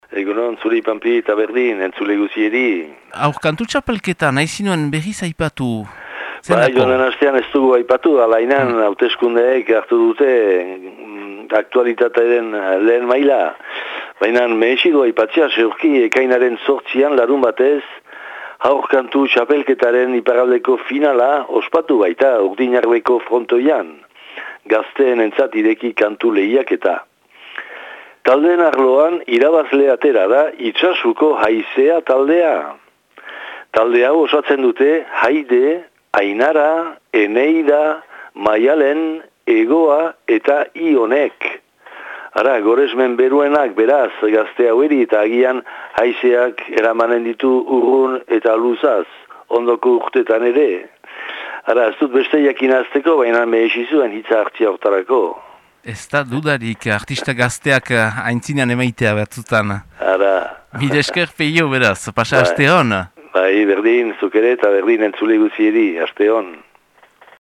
Ekainaren 17ko Itsasuko berriak